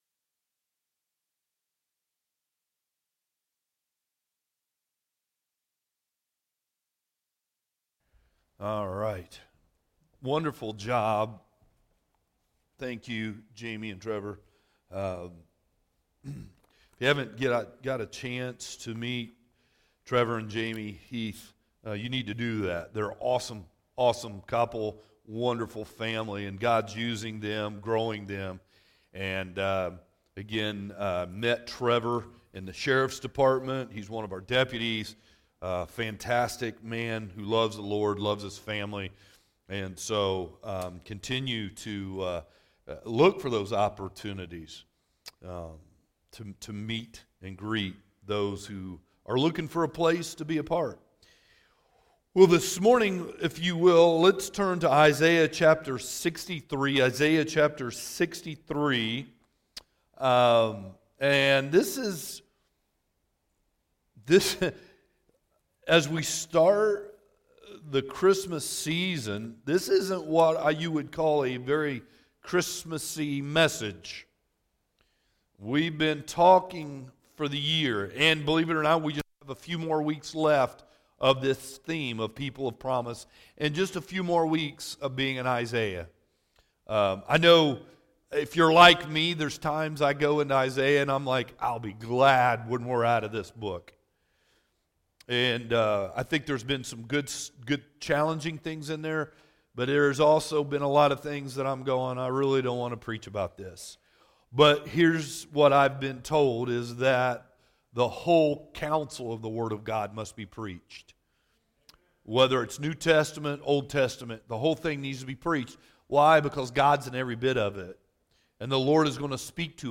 Sermons | Old Town Hill Baptist Church